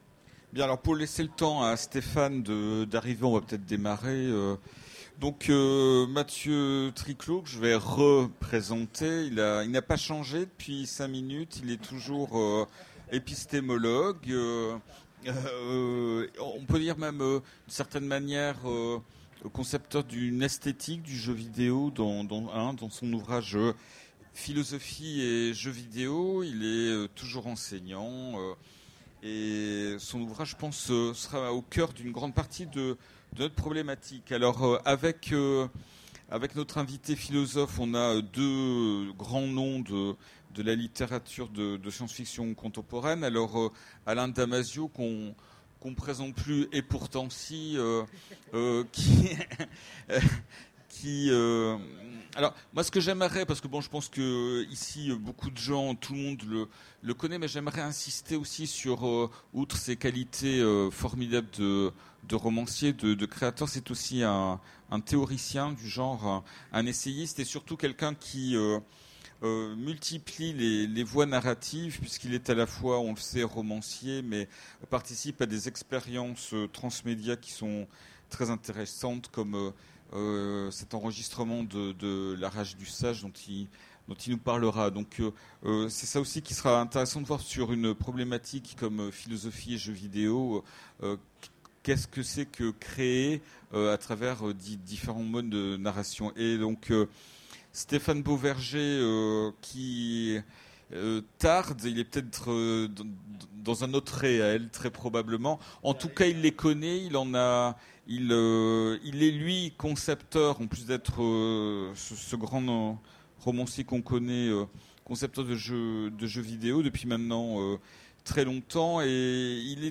Utopiales 13 : Conférence Philosophie et jeu vidéo